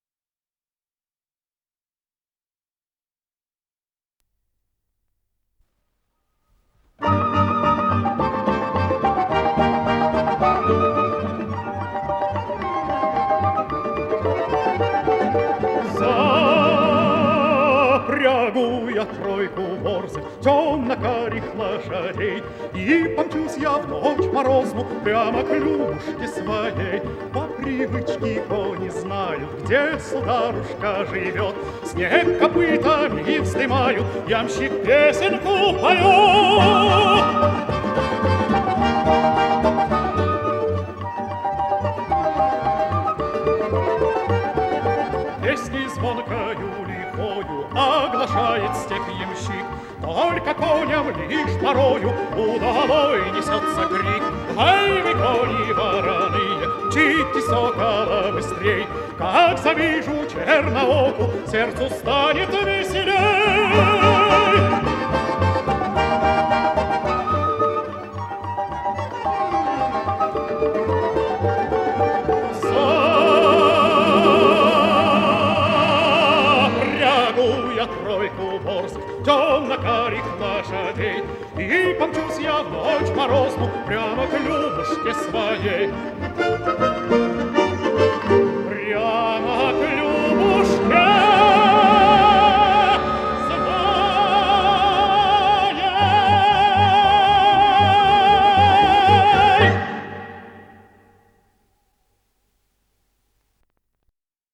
с профессиональной магнитной ленты
тенор
АккомпаниментЭксцентрик-шоу-группа "Джаз-балалайка"